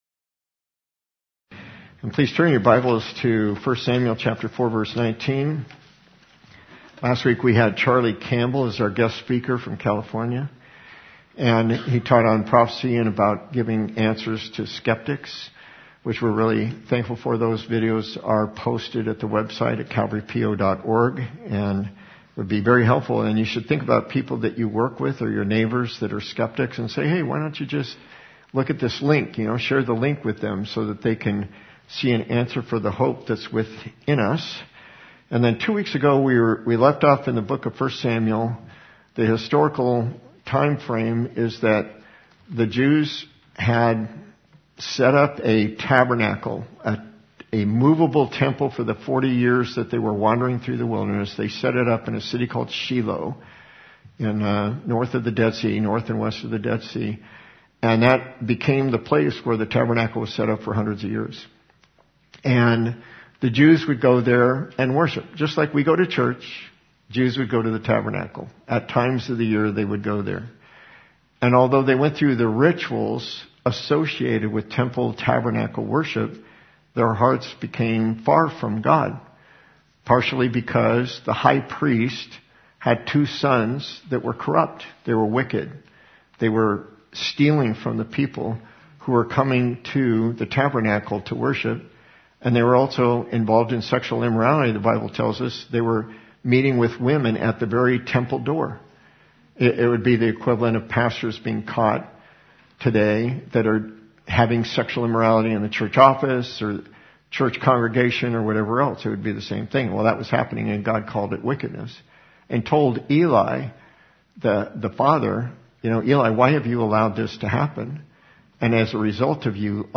Audio file includes communion service at the end The Philistines, having captured the Ark of the Covenant, watch as God demonstrates His superiority over their false god and afflicts them with tumors.